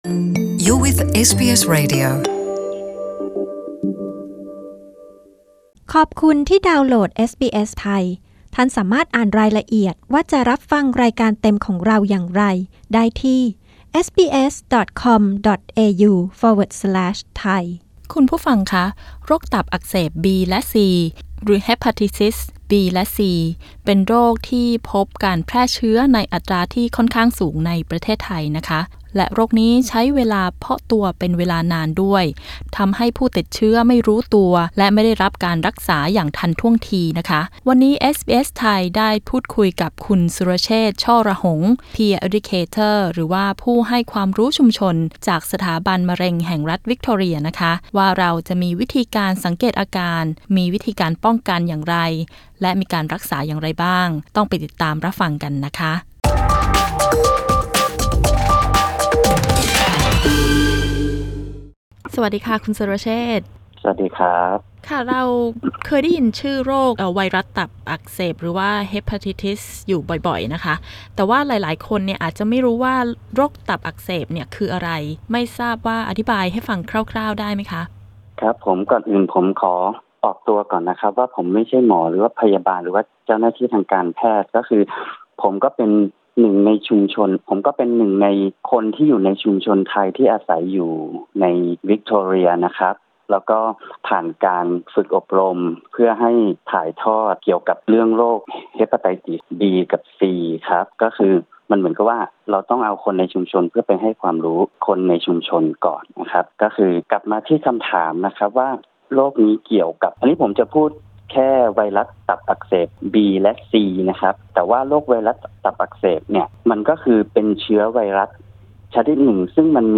โรคตับอักเสบชนิด บี และซี มีระยะเพาะตัวเป็นเวลานาน ผู้ติดเชื้ออาจไม่รู้ตัวและไม่ได้รับการรักษาจนกลายเป็นความเจ็บป่วยขัั้นรุนแรง คุณจะมีวิธีป้องกัน หรือถ้าสงสัยว่าตนเองมีความเสี่ยงจะทำอย่างไร ฟังคำแนะนำและข้อมูลที่เป็นประโยชน์จากผู้ให้ความรู้ชุมชนจากสถาบันมะเร็งวิคทอเรีย